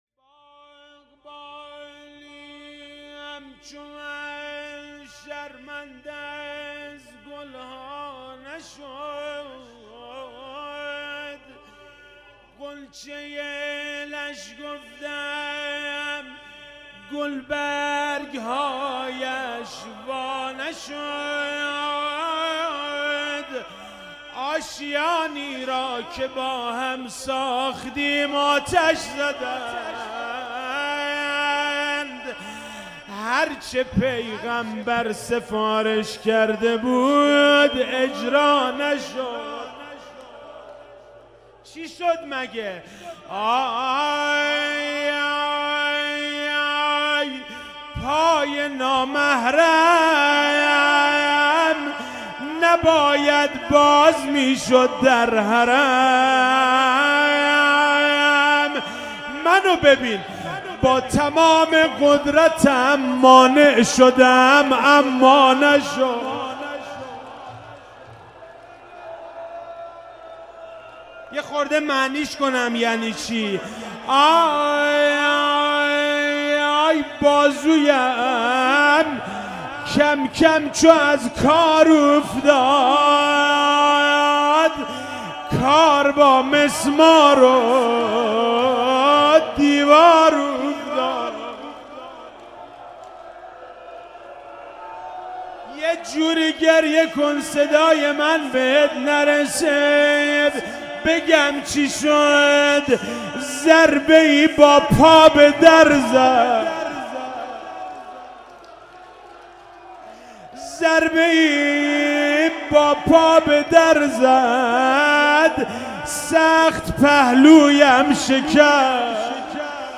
فاطمیه 96 - شب پنجم - روضه - باغبانی همچو من شرمنده از گل